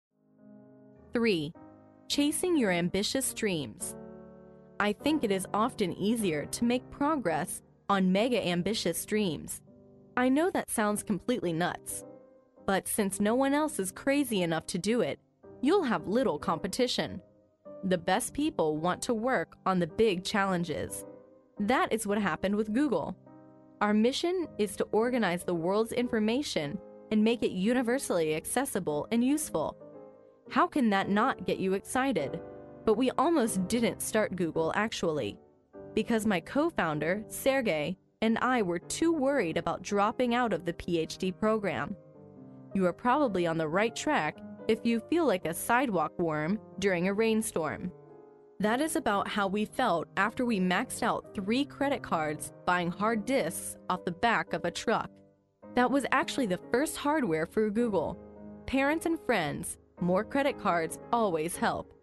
在线英语听力室历史英雄名人演讲 第34期:追逐远大的梦想(1)的听力文件下载, 《历史英雄名人演讲》栏目收录了国家领袖、政治人物、商界精英和作家记者艺人在重大场合的演讲，展现了伟人、精英的睿智。